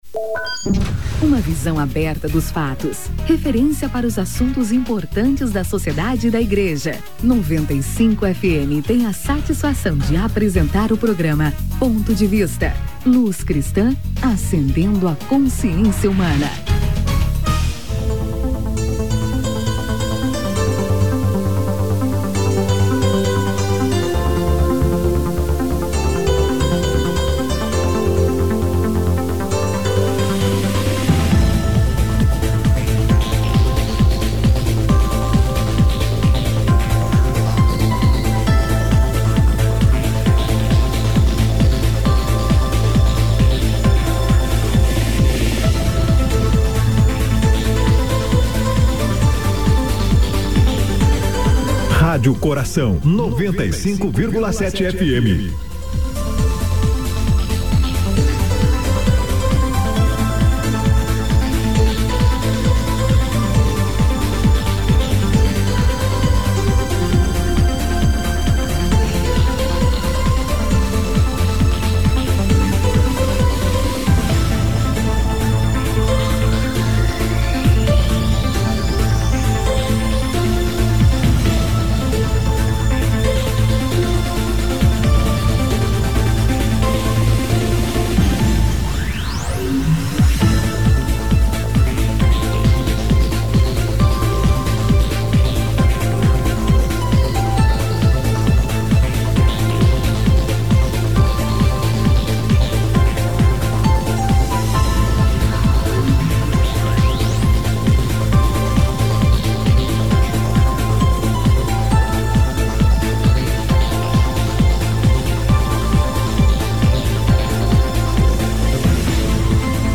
Ponto de Vista debate 'Formação teológica em tempos de crise'